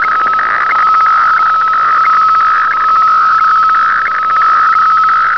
FAX - Facsimile
APT means that the station will send a 300 Hz or 675 Hz start tone which sets the IOC, followed by a pulsed synchronisation cycle of 95% white and 5% black. At the end of the fax picture a stop tone of 450Hz is sent.
FAX 120 RPM, IOC 576, FC 1750 Hz  FAX 120 RPM, IOC 576, FC 1750 Hz
fax.wav